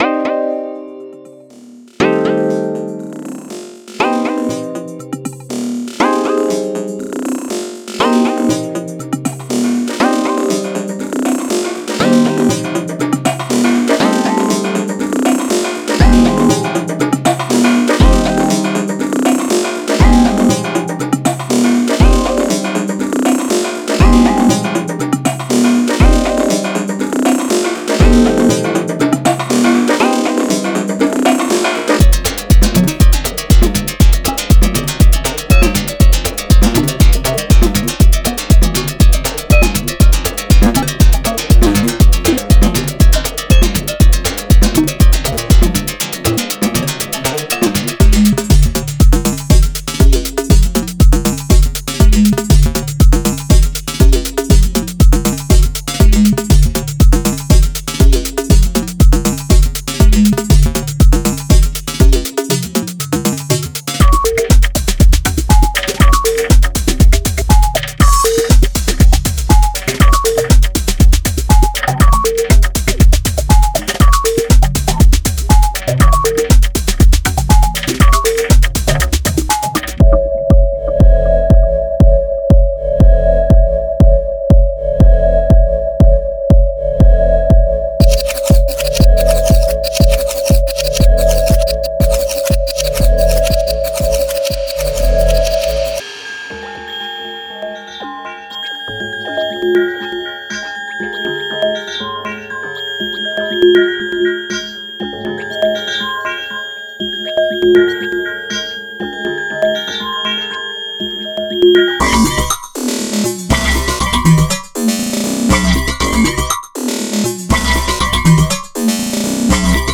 Deep Minimal Trax Maker 6 – Mini DAW